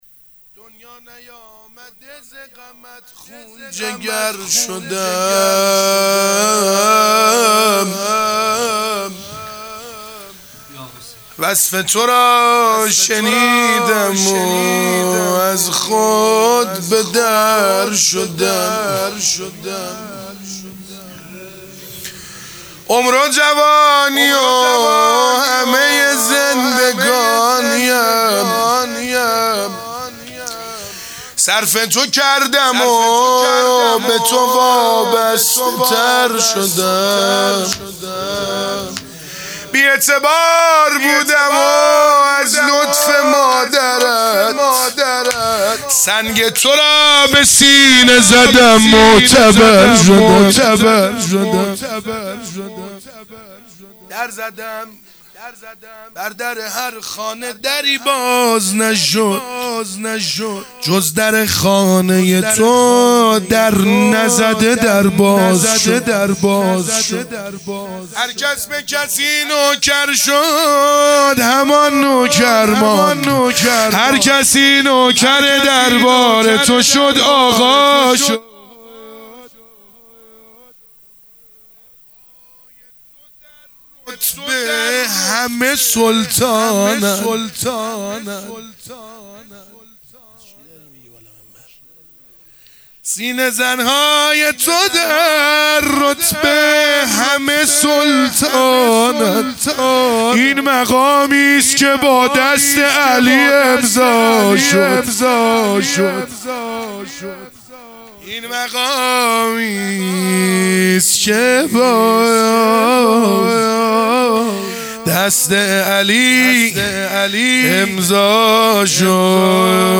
شهادت حضرت ام البنین (س) | 22دی ماه1400 | شعرخوانی